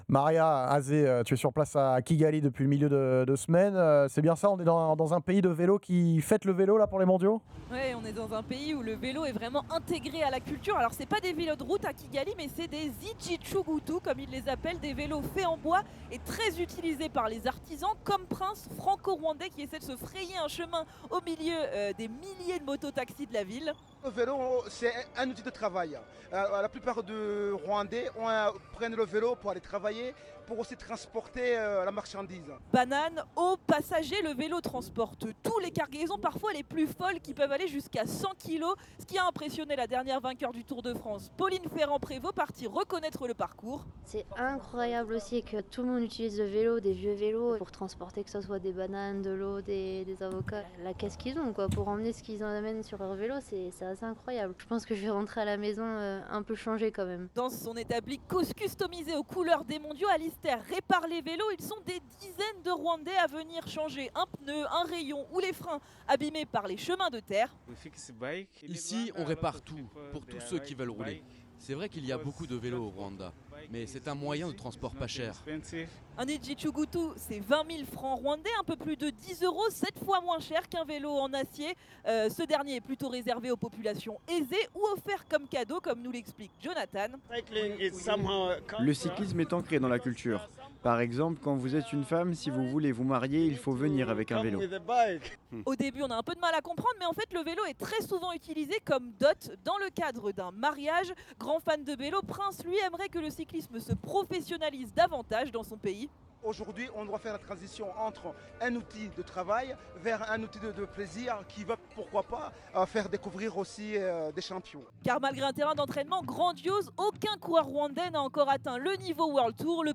Reportage fait depuis Kigali, au Rwanda dans le cadre des championnats du monde de cyclisme. Les sons ont été tournés la veille, lors de la course des espoirs. Je l’ai speaké en direct dans les Grandes Gueules du sport le samedi 27 septembre. Reportage sur un pays où le vélo est plus qu’un sport.